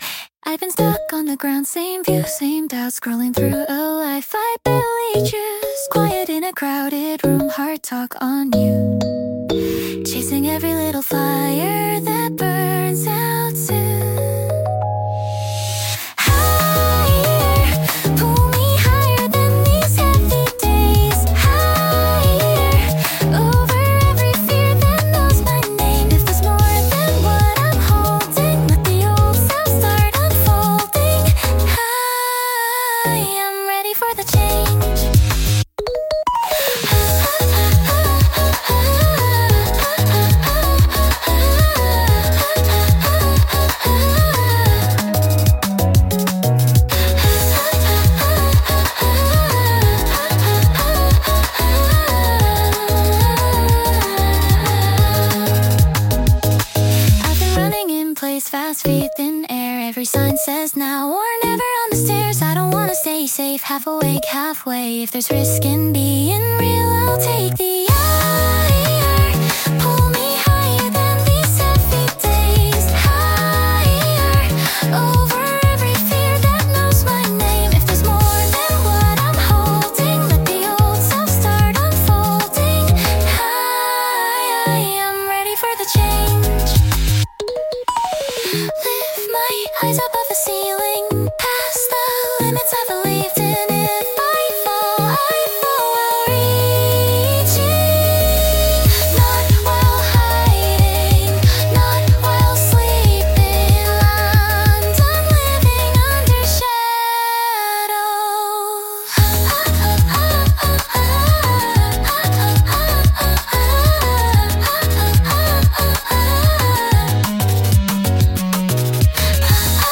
アニメ音楽は、日本のアニメ主題歌をイメージしたジャンルで、ポップでキャッチーなメロディとドラマチックな展開が特徴です。
明るくエネルギッシュな曲調から感動的なバラードまで幅広く、視聴者の感情を引き立てる要素が豊富に詰まっています。